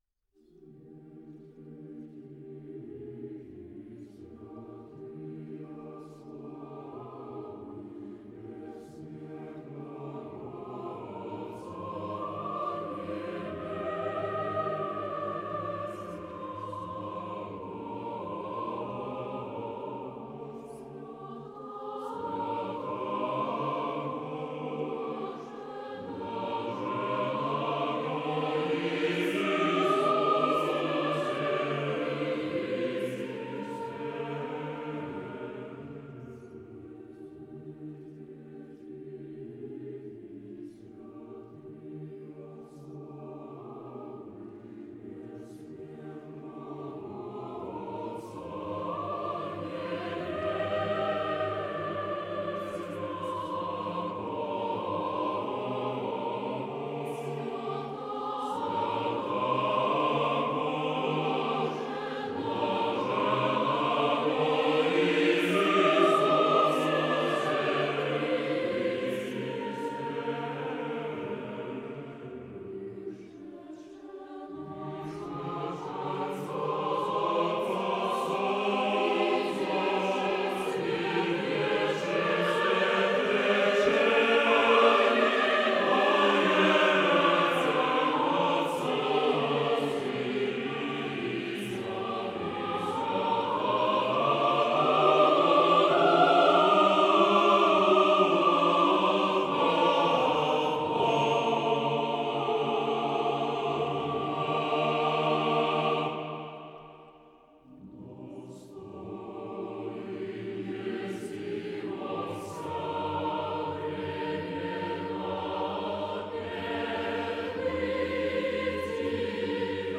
Воскресное литургическое пение 5.00 MB